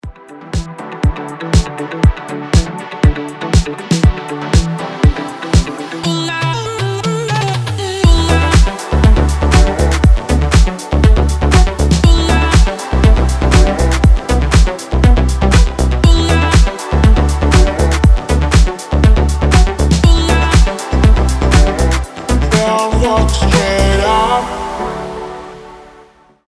• Качество: 320, Stereo
nu disco